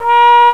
OLDHORN.WAV